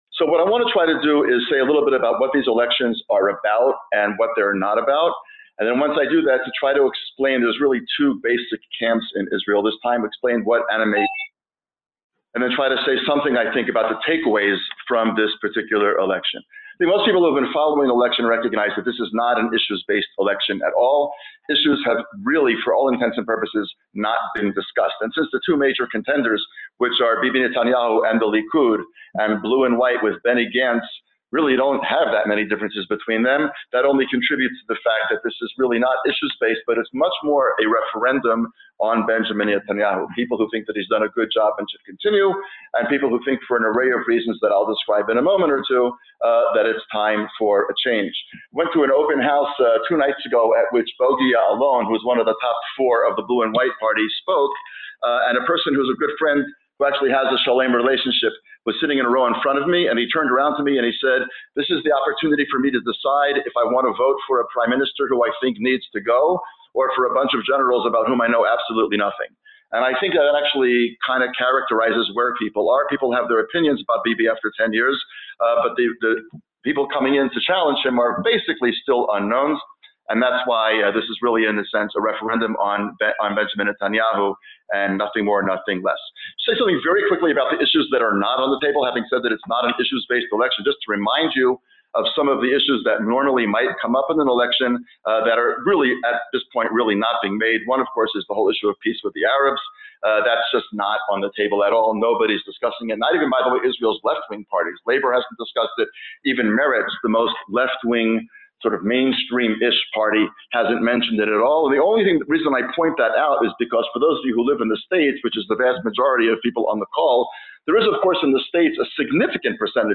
These and other questions were the subject of Shalem Senior Vice President and Koret Distinguished Fellow Dr. Daniel Gordis’s recent briefing call with members of the Shalem College community on April 4th, an essential primer for understanding the significance and implications of the 2019 elections in the Jewish state.